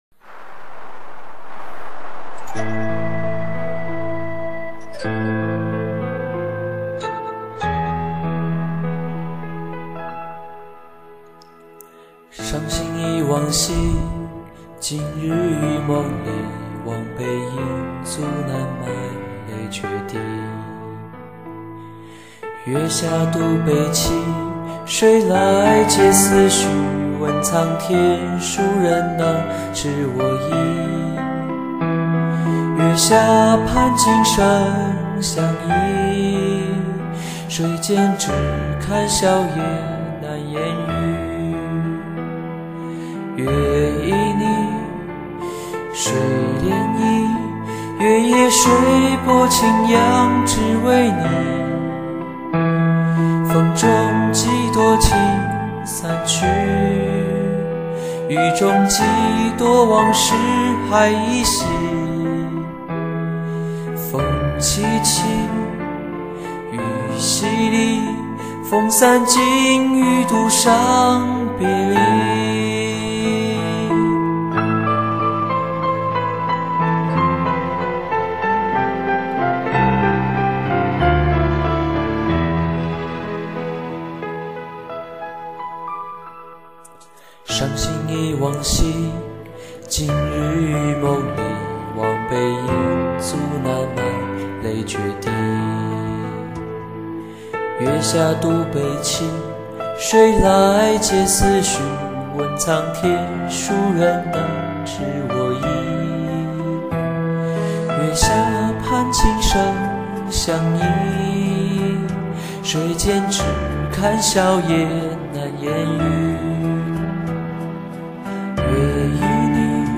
但是聽聲音還感覺是個小男生啊。。。